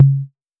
Techno Tom 02.wav